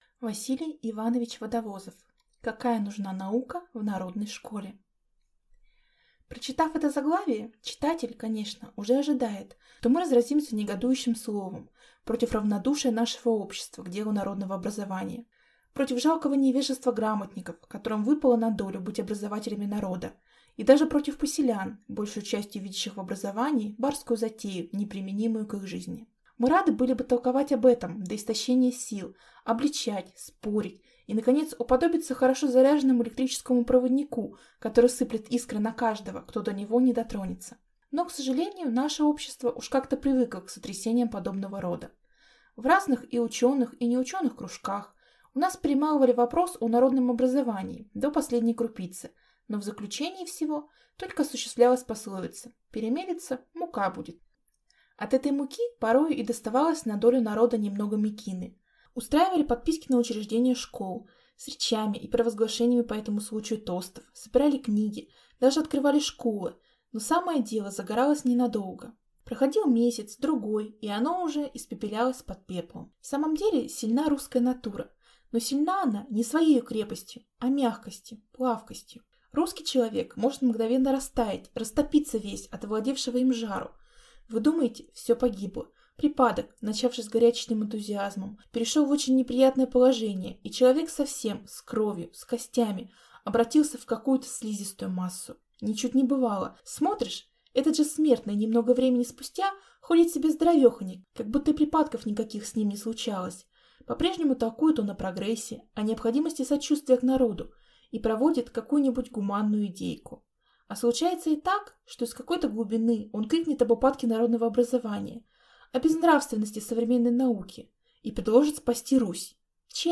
Аудиокнига Какая нужна наука в народной школе | Библиотека аудиокниг